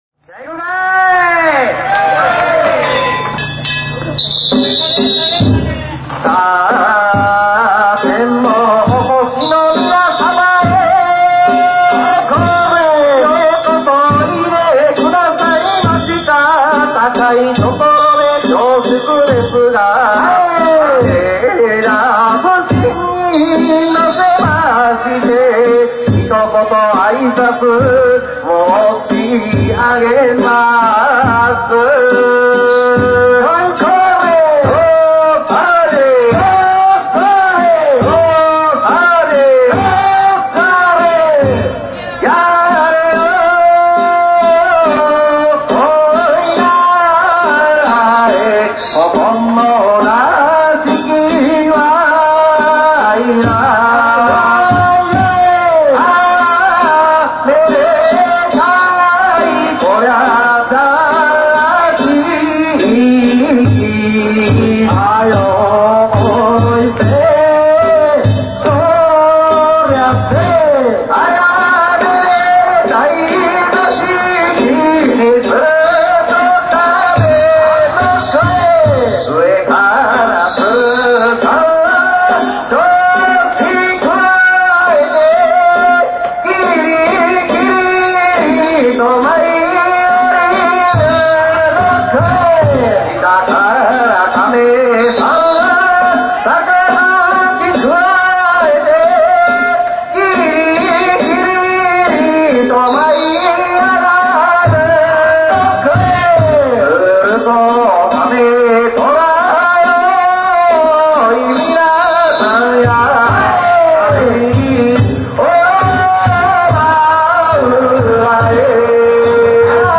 前田地車お披露目曳行
平成２８年２月２８日、大阪狭山市の前田地車お披露目曳行を見に行ってきました。
曳き唄の始まりの挨拶もええ感じですねぇ♪
曳き唄唄いながら進む前田地車